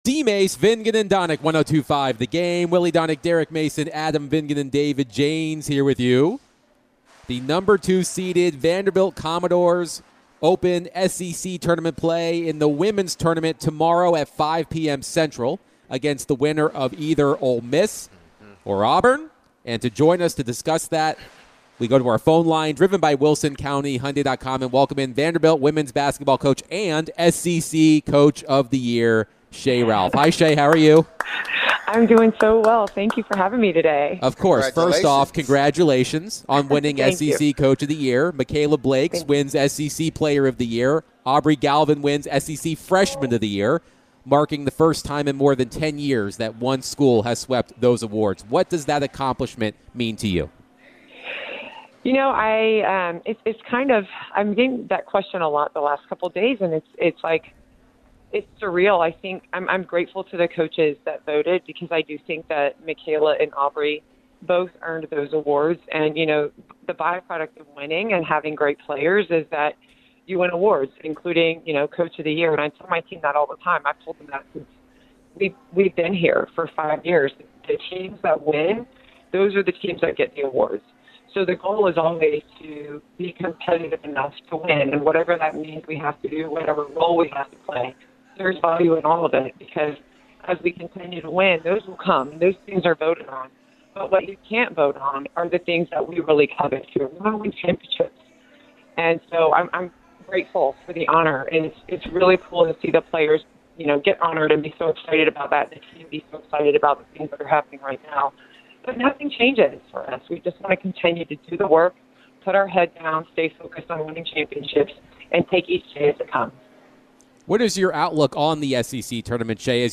Vandy WBB Head Coach Shea Ralph joined DVD to discuss her team's success this season, the SEC Tournament game tomorrow, and more.